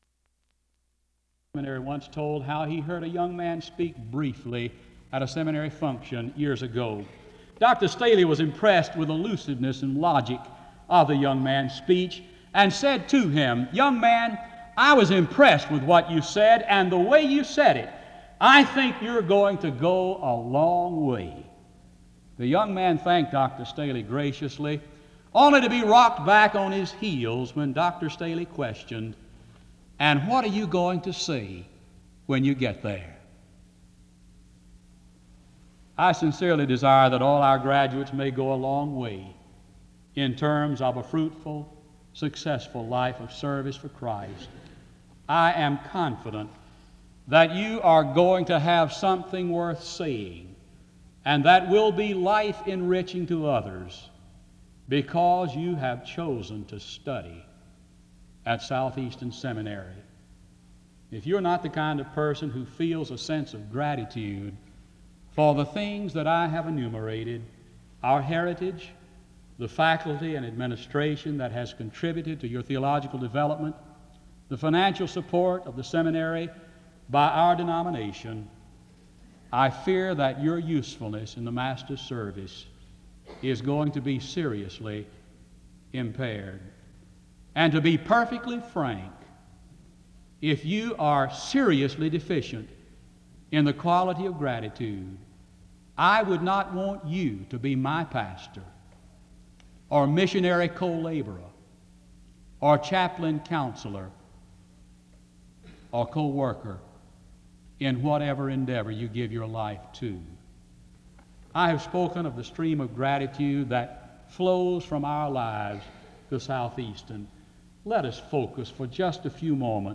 SEBTS Commencement